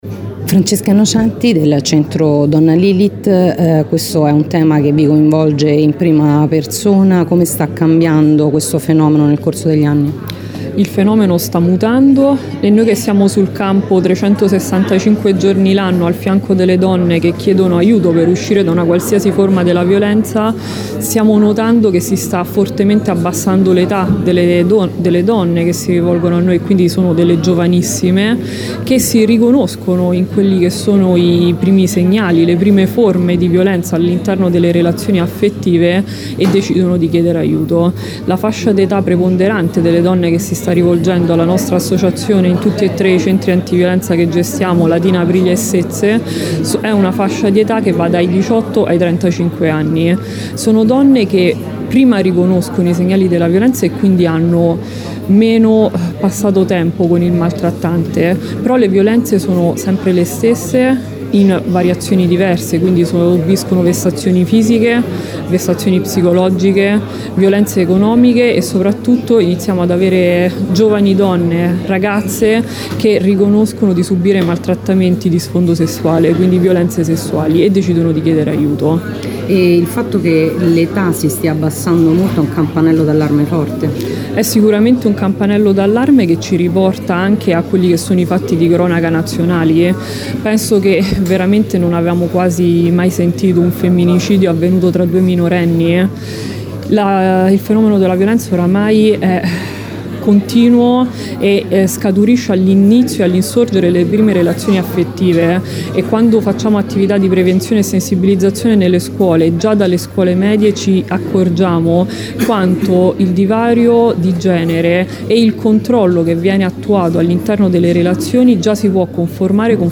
L’evento, che precede la Giornata Internazionale per l’eliminazione della violenza contro le donne, si è tenuto nella sala conferenze del Polo Pontino della Sapienza con la partecipazione di molti attori istituzionali.
Le interviste contenute in questo articolo